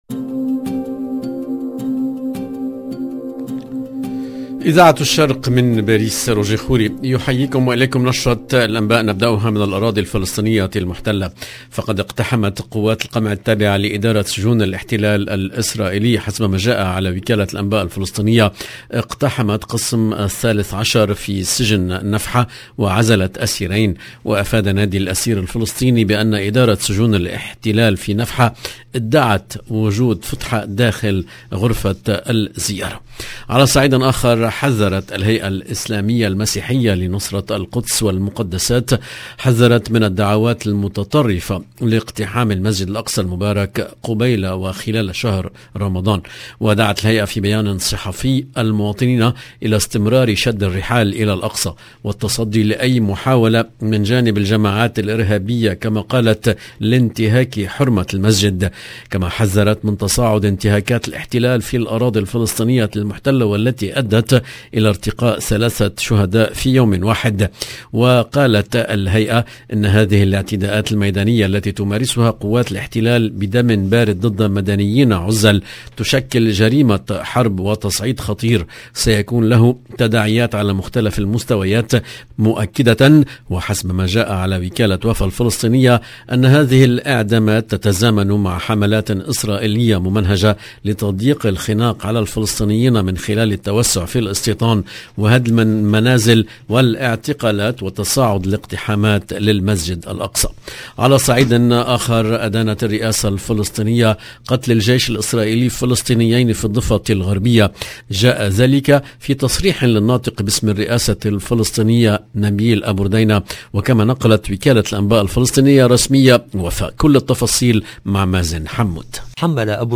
Palestine 16 mars 2022 - 15 min 59 sec LE JOURNAL DU SOIR EN LANGUE ARABE DU 16/03/22 LB JOURNAL EN LANGUE ARABE الهيئة الاسلامية المسيحية لنصرة القدس والمقدسات تحذر من الدعوات المتطرفة لاقتحام المسجد الأقصى المبارك قبيل وخلال شهر رمضان المبارك …